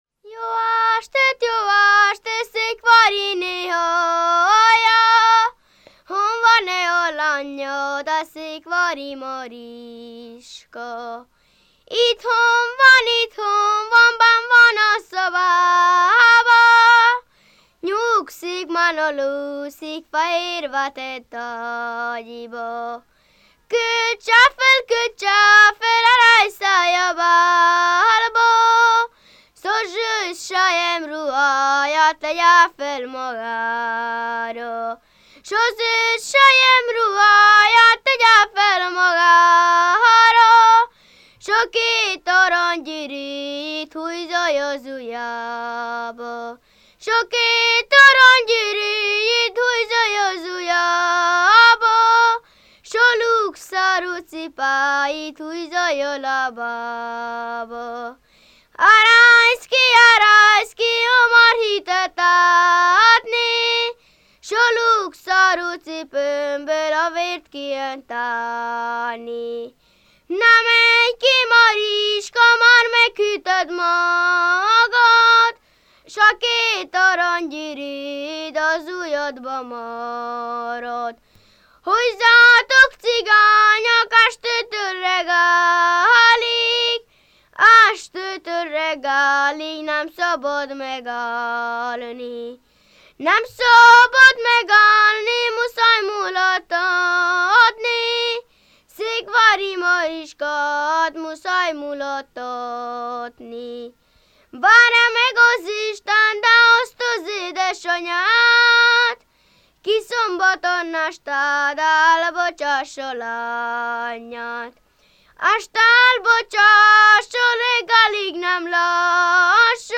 ének
ballada
Lészped
Moldva (Moldva és Bukovina)